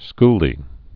(sklē)